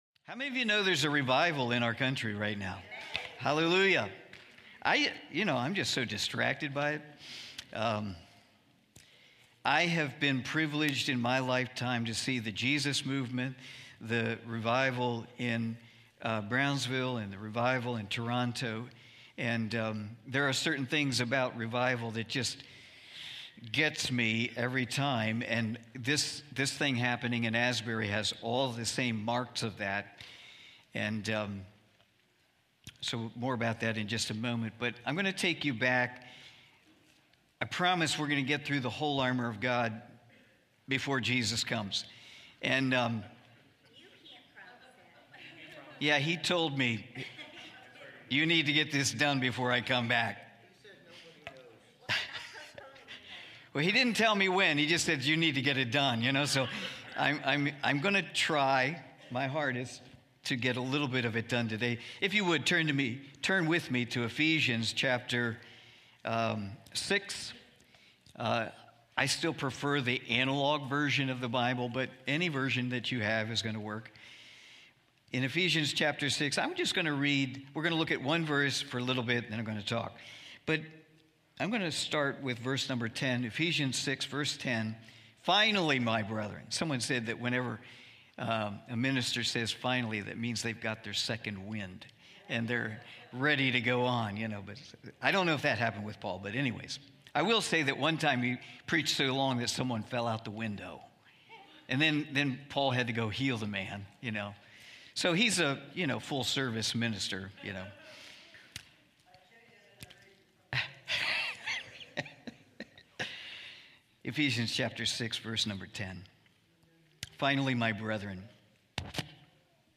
Sunday morning service, livestreamed from Wormleysburg, PA.